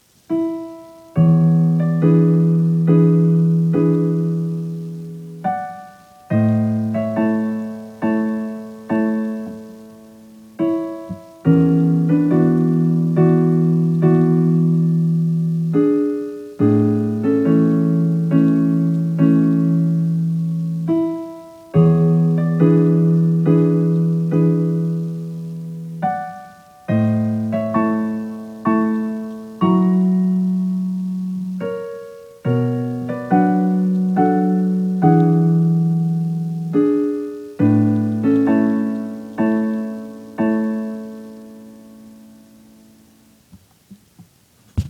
Piano, pièce #2.m4a
piano-piece-2.m4a